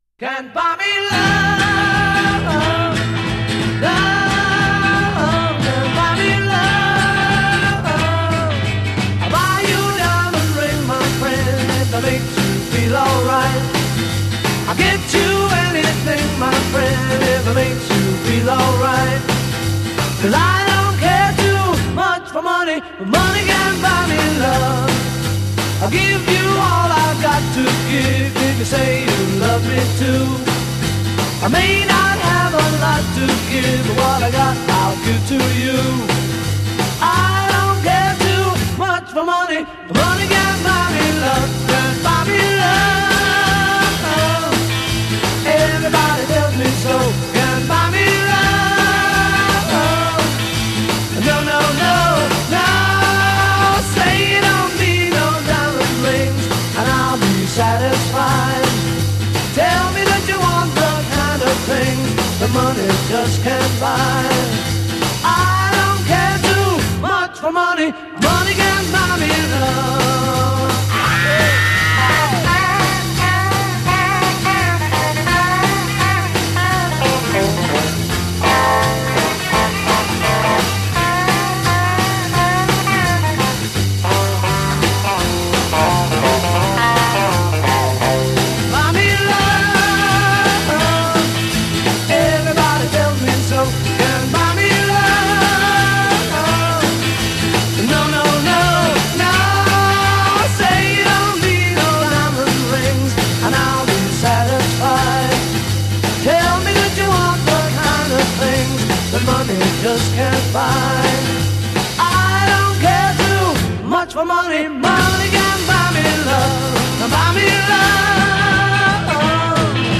chorus 8   moves to E minor (e, a, C, e, a, d, G) d